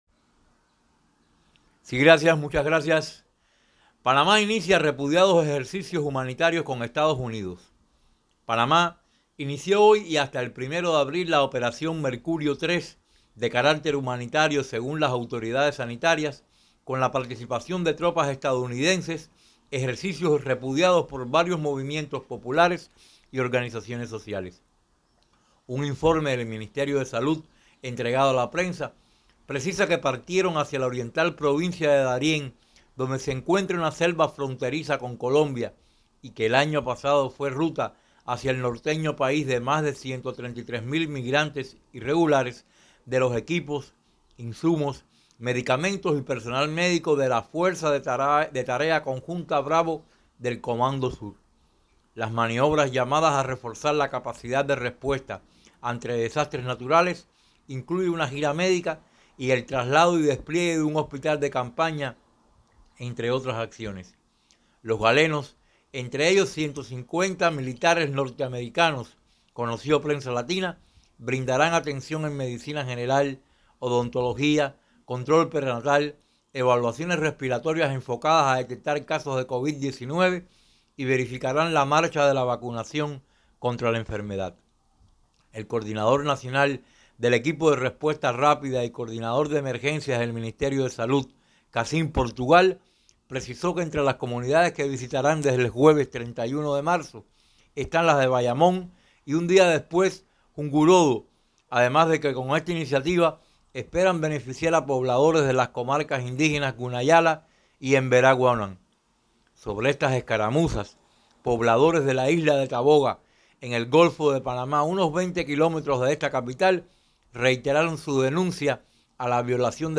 reporte-Radio.MERCURIO-III.mp3